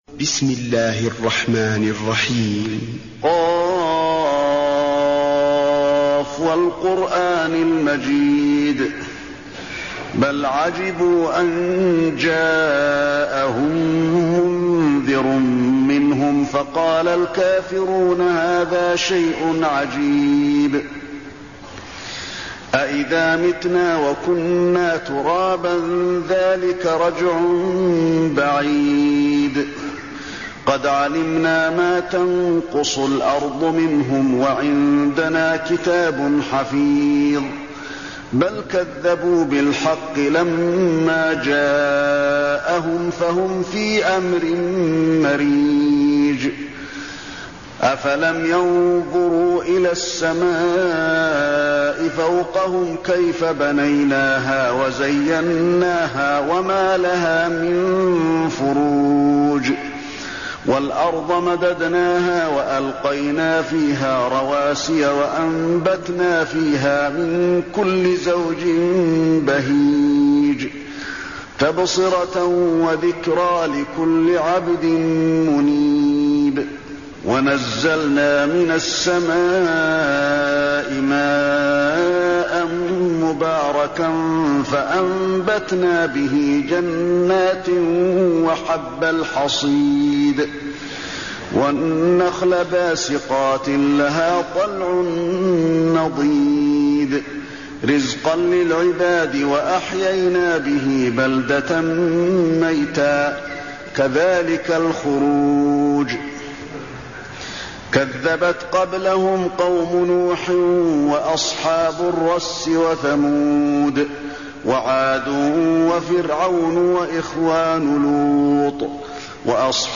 المكان: المسجد النبوي ق